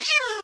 MinecraftConsoles / Minecraft.Client / Windows64Media / Sound / Minecraft / mob / cat / hit3.ogg
hit3.ogg